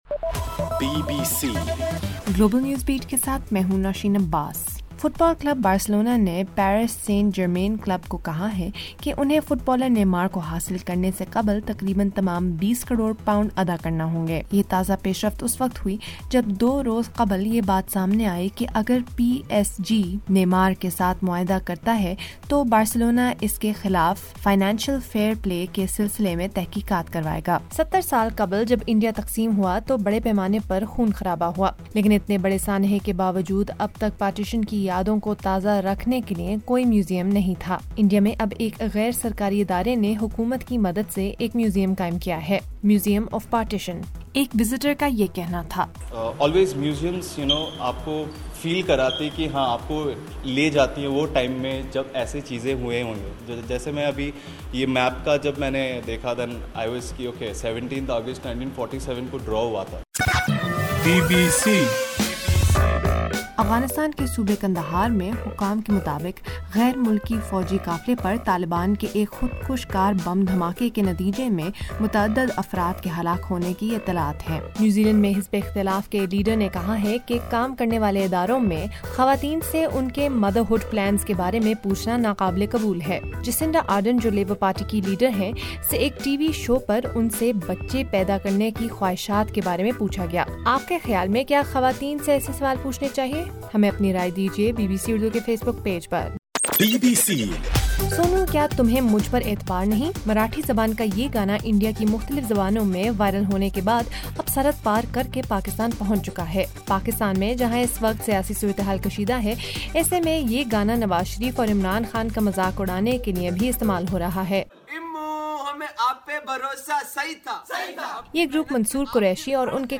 اگست 02 :رات 12 بجے کا نیوز بُلیٹن
دس منٹ کا نیوز بُلیٹن روزانہ پاکستانی وقت کے مطابق شام 5 بجے، 6 بجے اور پھر 7 بجے۔